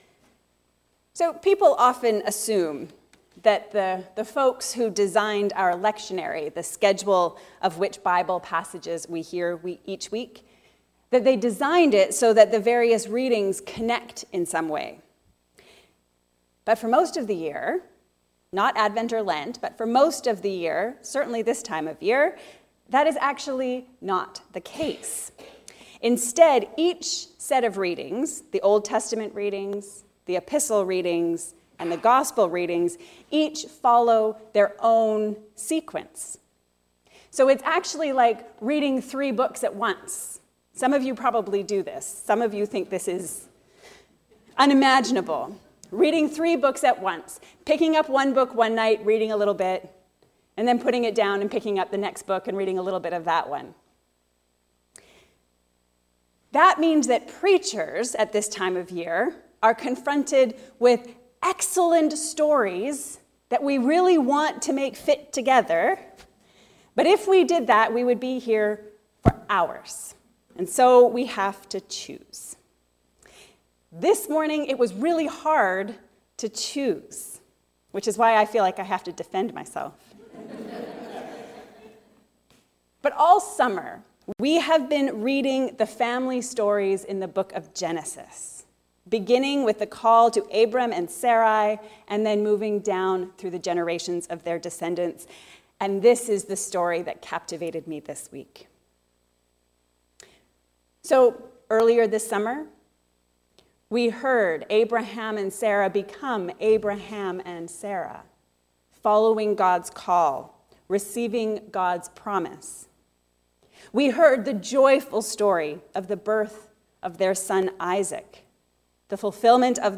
Where is God in our stories? A sermon on the family stories of Genesis.